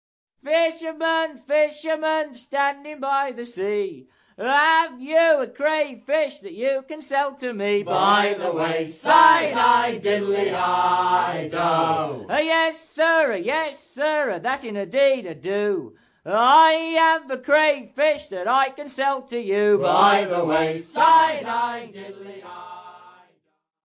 Songs of the North Atlantic Sailing Packets